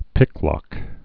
(pĭklŏk)